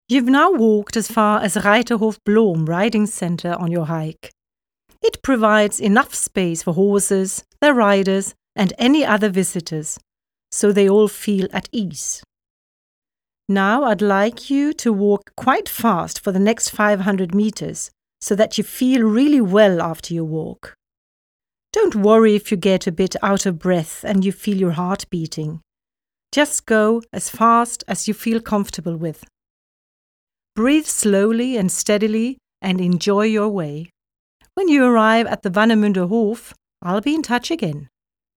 Audioguide
Enjoy your walk and the audio tour with easy exercises that you can do along the way!
Responsible for the sound recordings: Sound studio at Stralsund University of Applied Sciences.